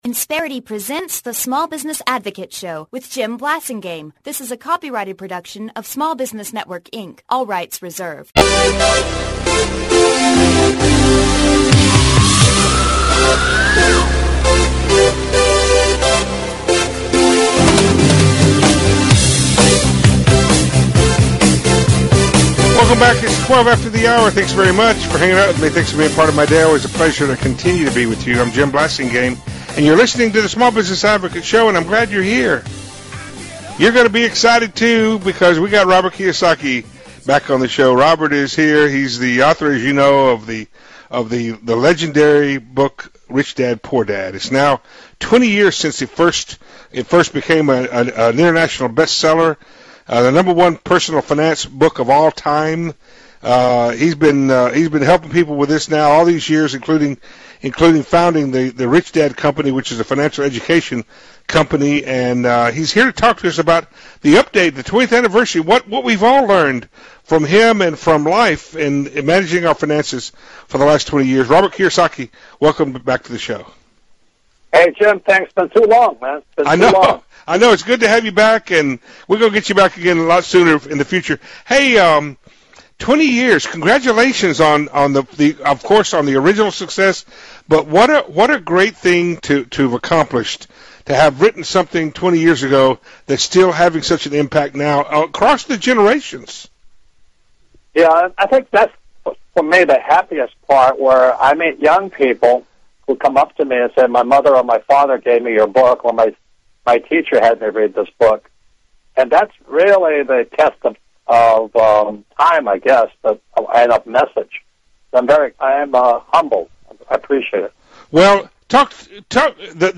Archived Interviews